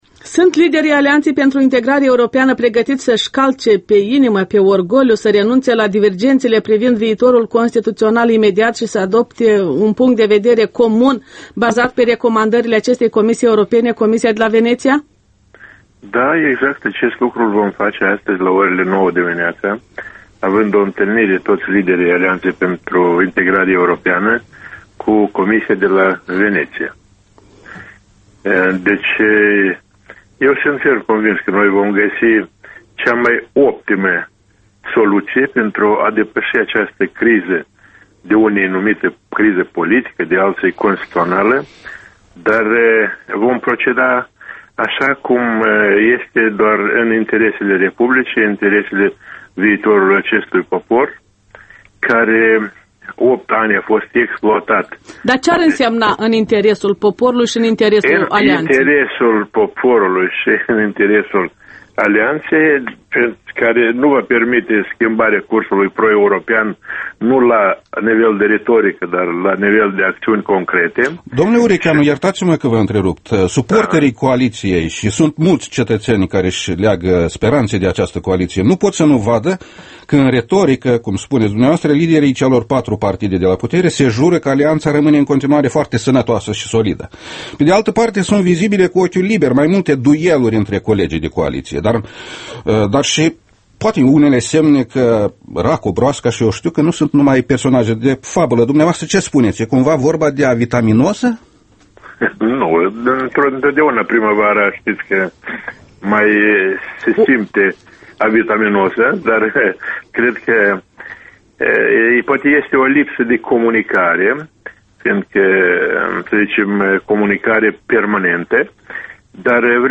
Interviul matinal EL: cu Serafim Urechean